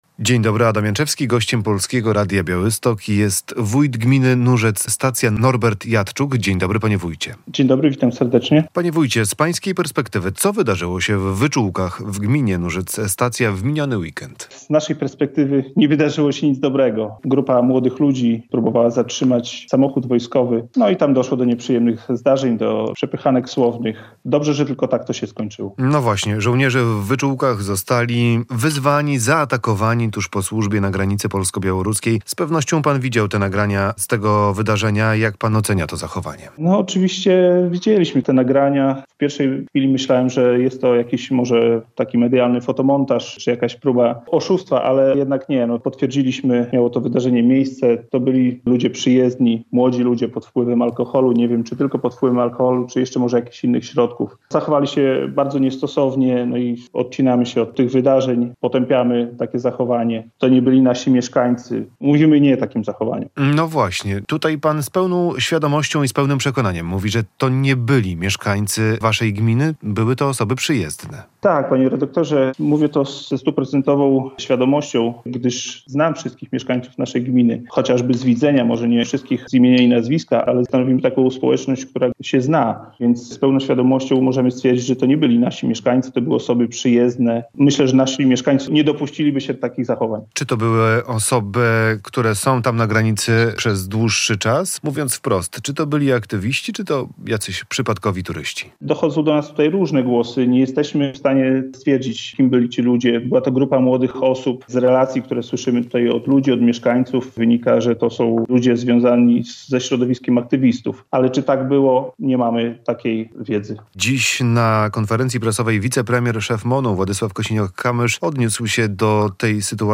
Norbert Jadczuk - Norbert Jadczuk, wójt gminy Nurzec-Stacja